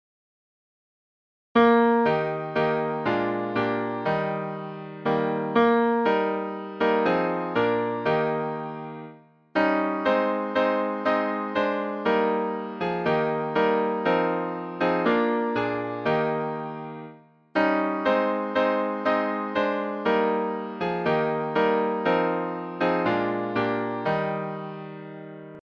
Music by: German folk tune